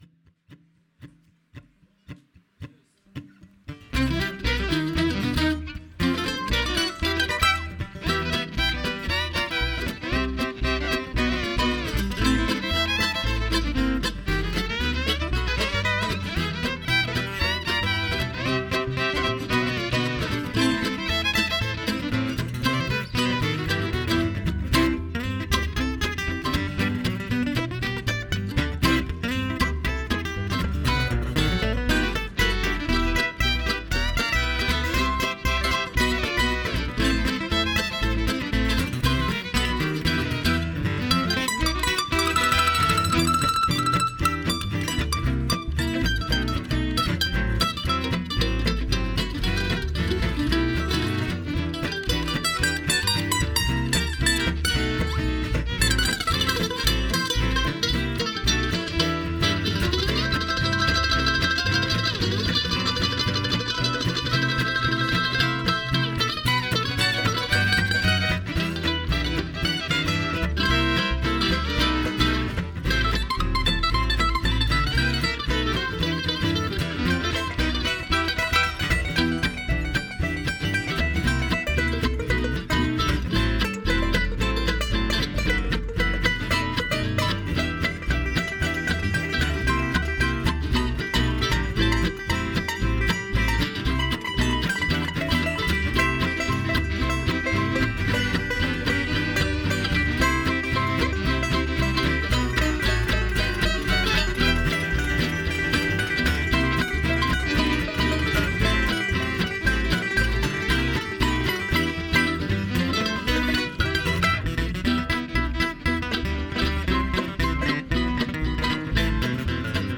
An Original Swing Tune
As promised, here is the second half of this wonderful show.